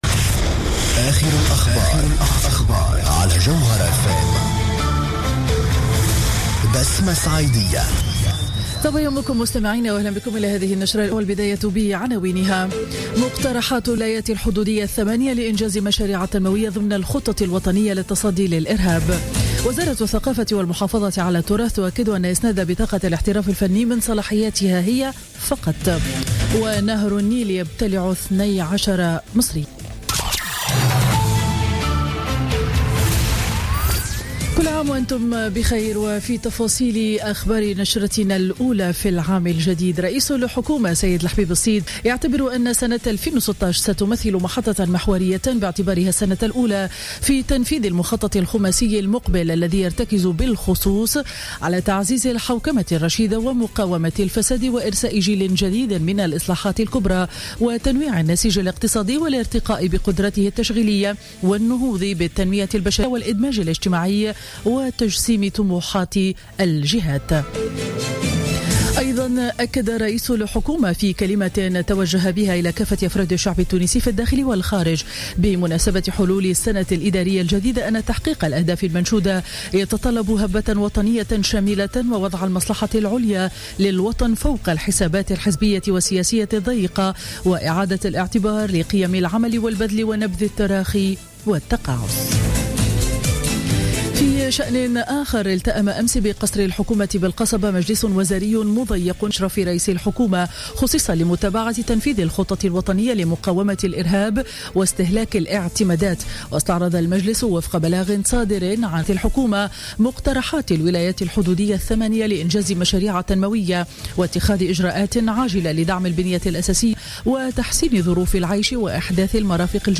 نشرة أخبار السابعة صباحا ليوم الجمعة 1 جانفي 2016